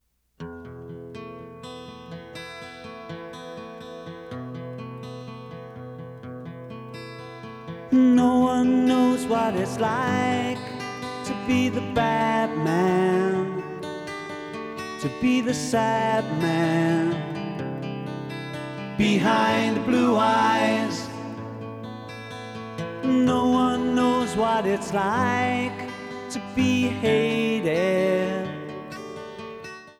Excellent mastering.